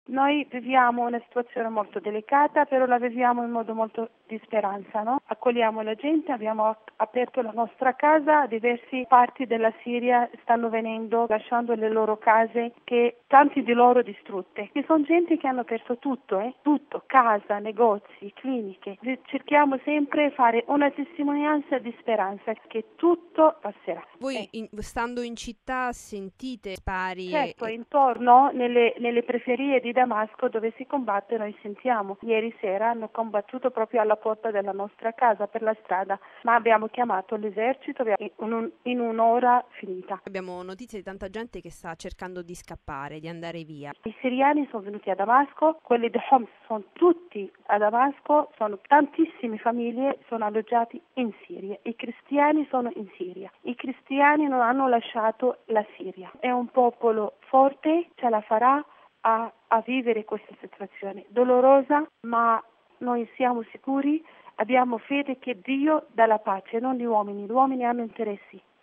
raggiunta telefonicamente a Damasco:RealAudio